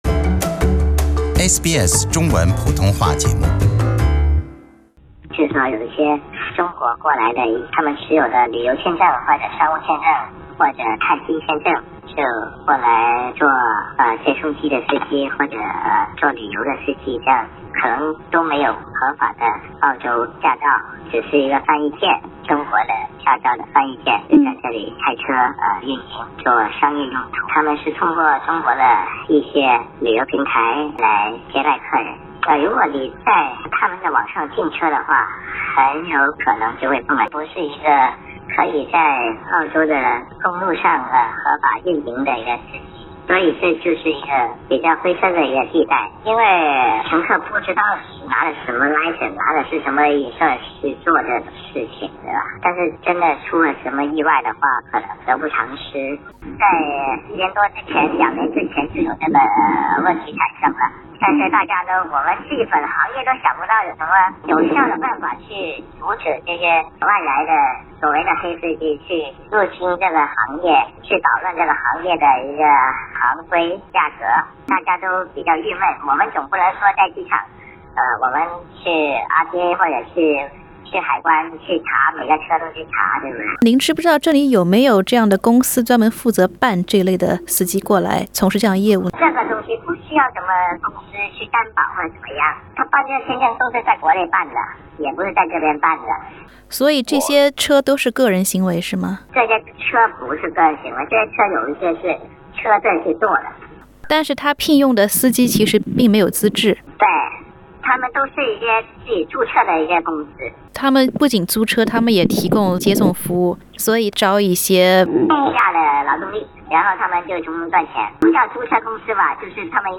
應Uber司機要求，我們在寀訪時對他的聲音做了變聲處理。